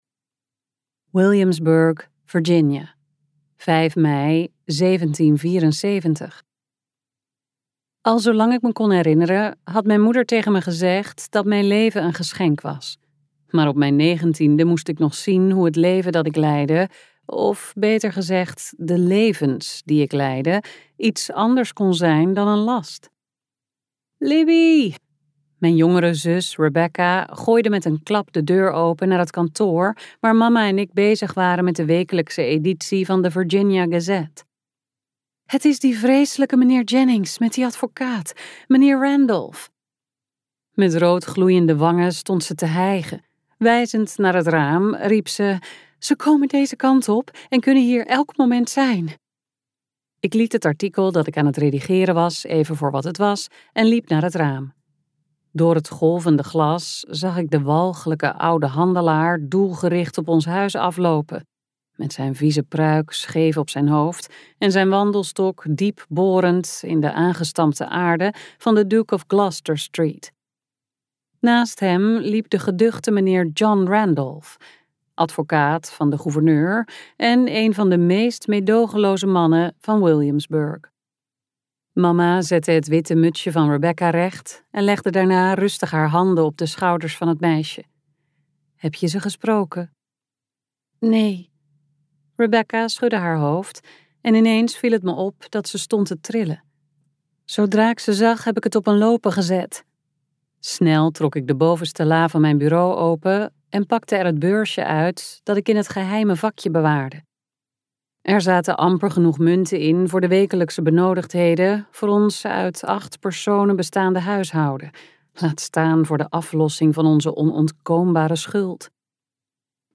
KokBoekencentrum | Als de dag aanbreekt luisterboek